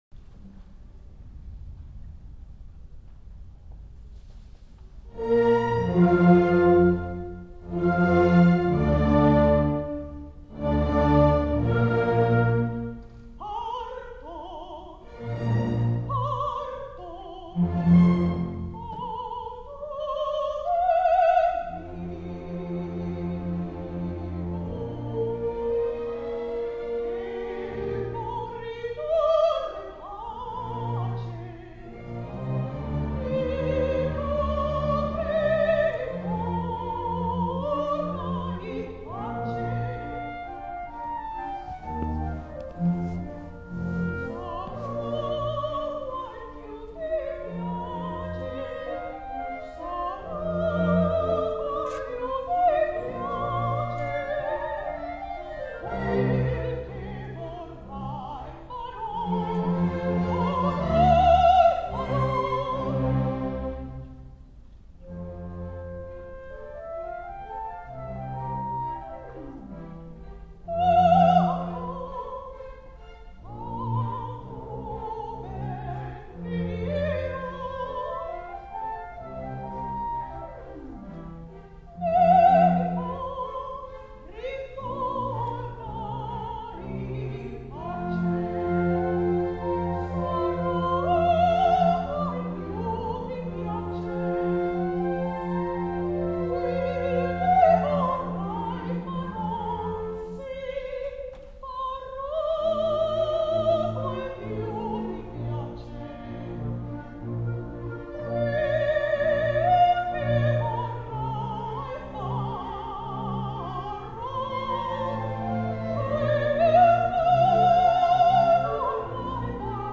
Parto ma tu ben mio from La Clemenza di Tito by Mozart with the Alhambra Orchestra